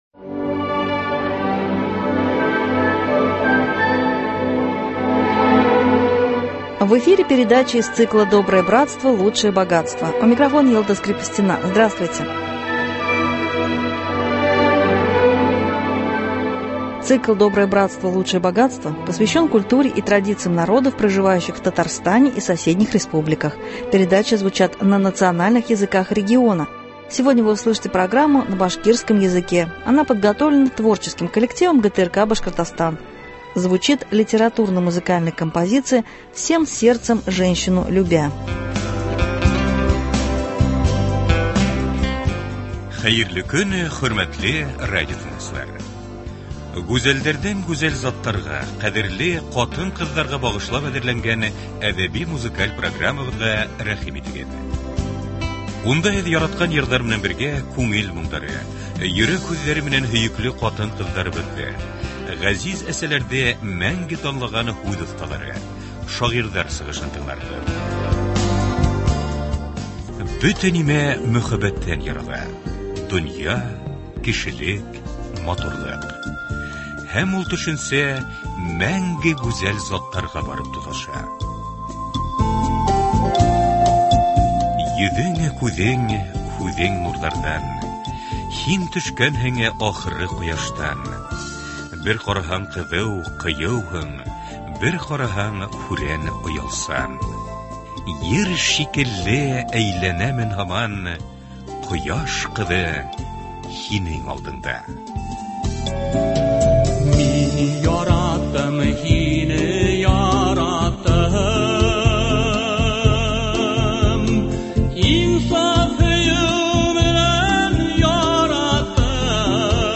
Литературно- музыкальная композиция на башкирском языке.